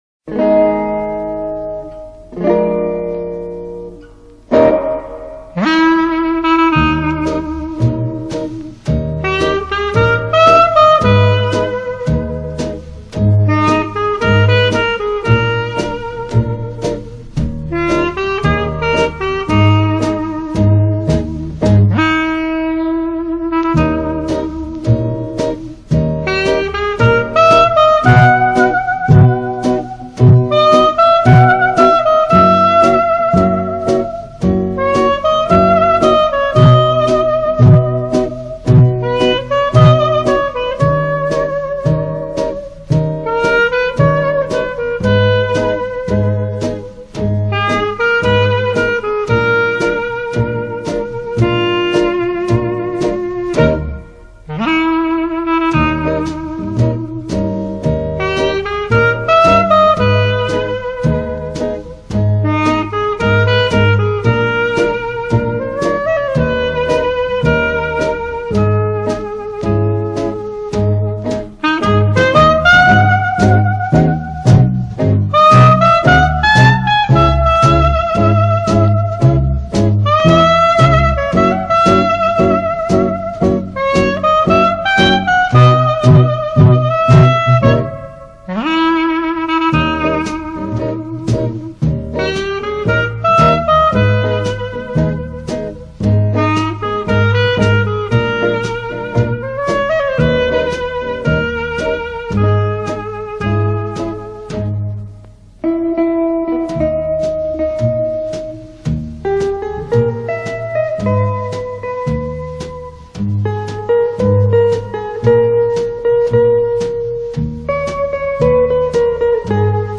_Танго___Маленький_цветок_MP3_128K